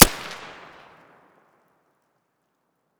Index of /fastdl/sound/weapons/mp9